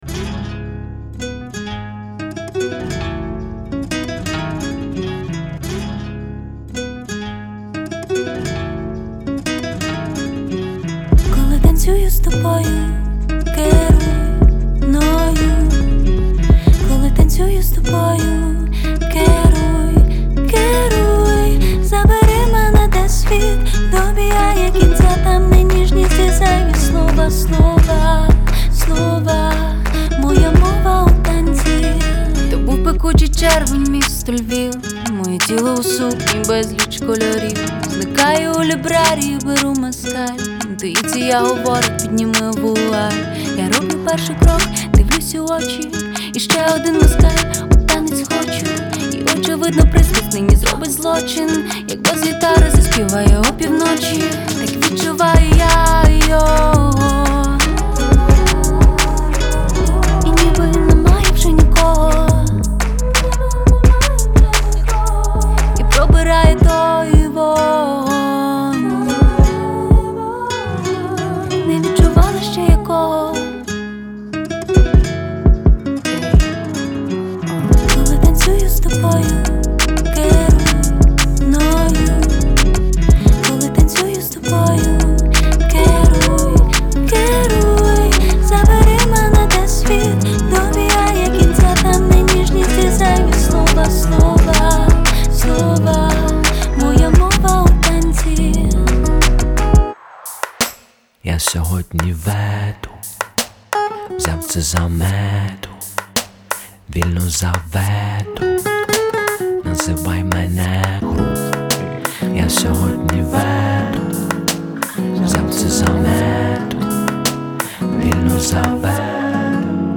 • Жанр: Soul